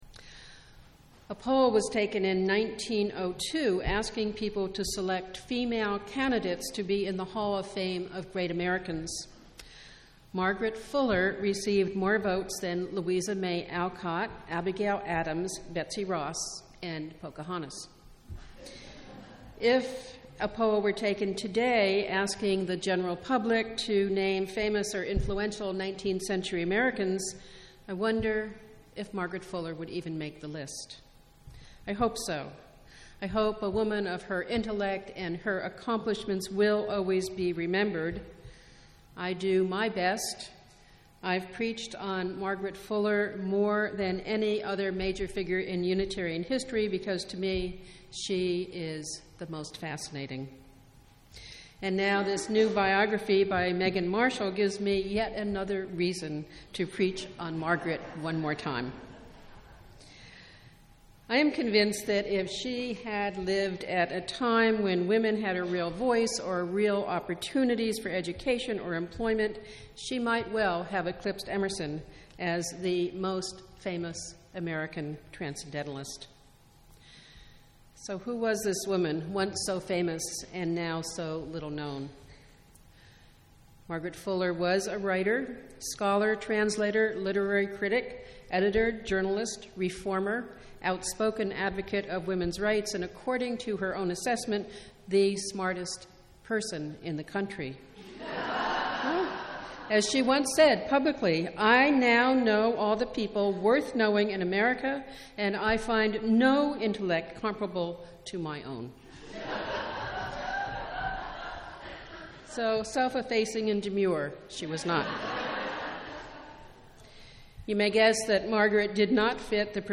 Margaret Fuller, contemporary of Emerson and Parker, was a provocateur, a crusader for women’s rights and one of the great intellects of her time. This sermon tells her remarkable and ultimately tragic story.